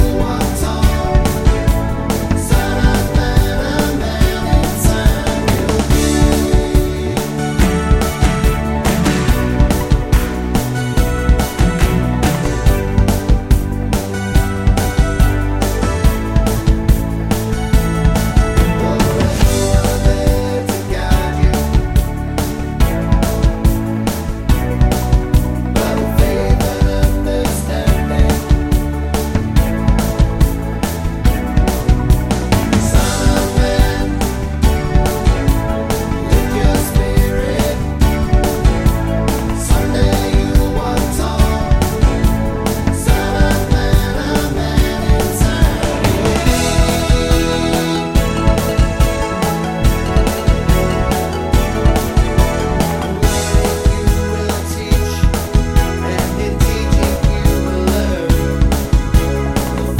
no Backing Vocals Soundtracks 2:45 Buy £1.50